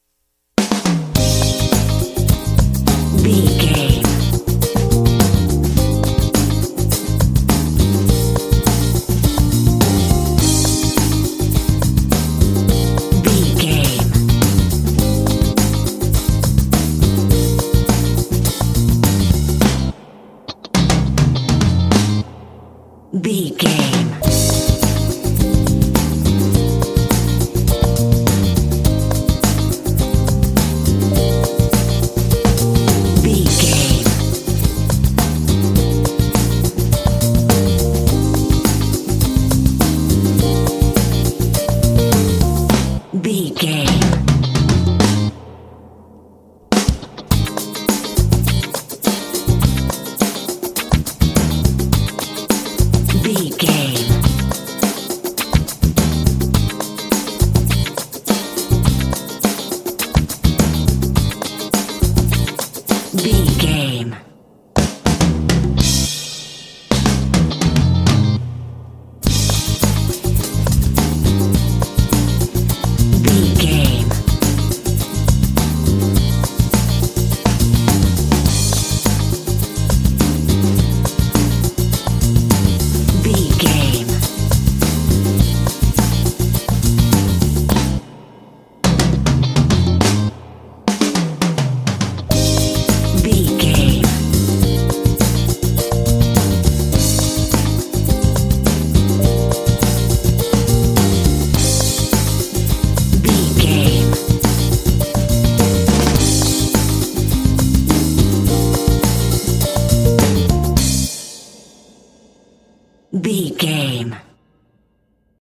Uplifting
Aeolian/Minor
groovy
driving
energetic
electric piano
electric guitar
bass guitar
drums